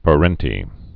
(pə-rĕntē)